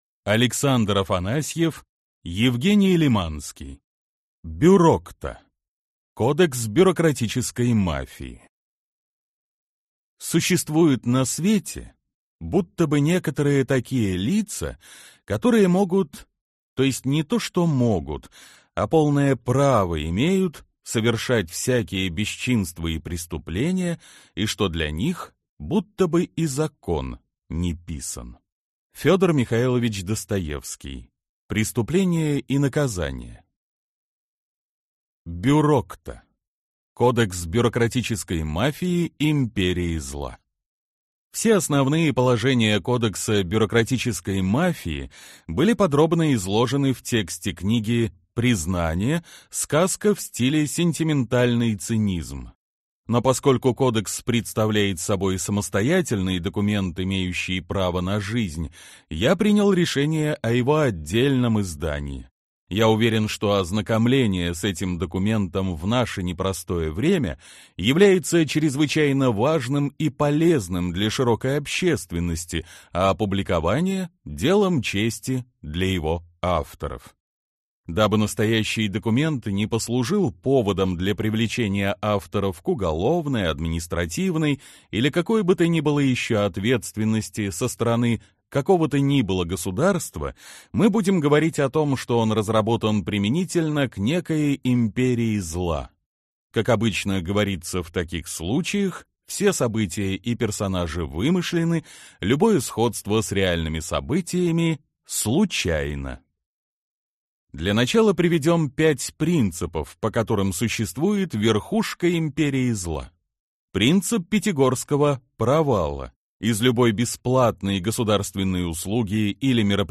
Аудиокнига Бюрокта/Bureaucta. Кодекс бюрократической мафии / The Code of the bureaucratic mafia | Библиотека аудиокниг